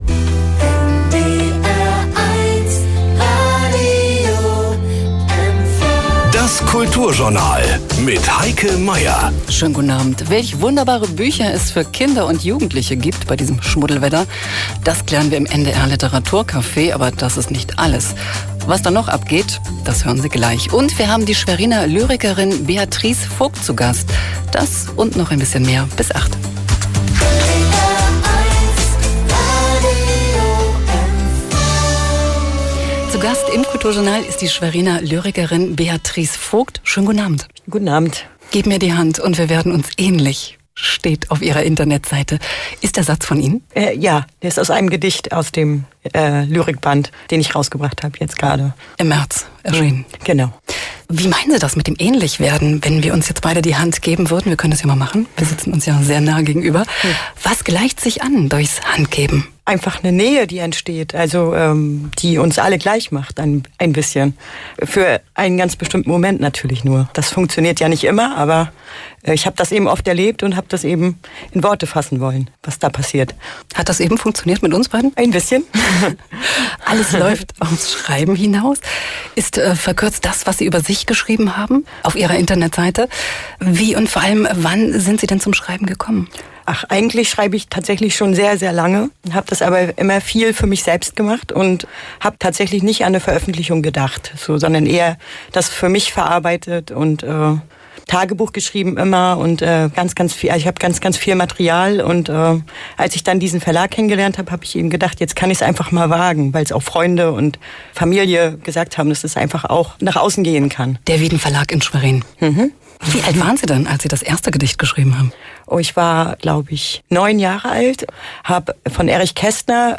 Interview NDR 1-MV “Kulturjournal”